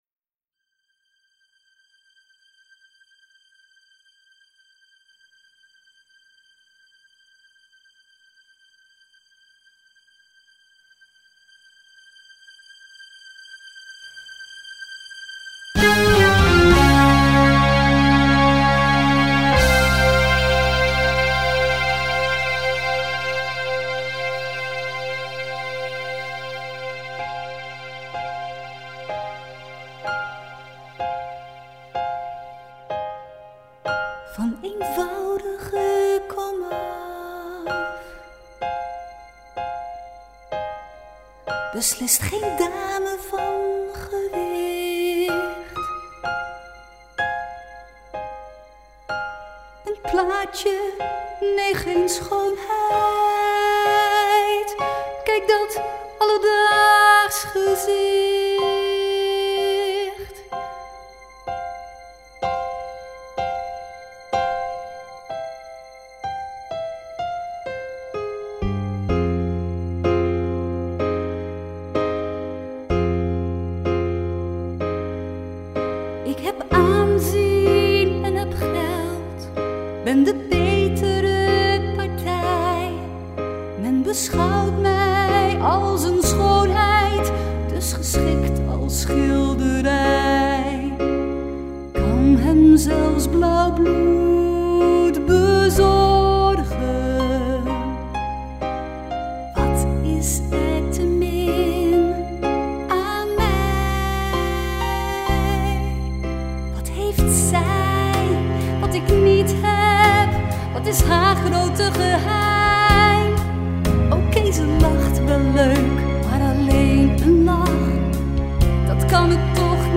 Uit “Da Vinci” (musical)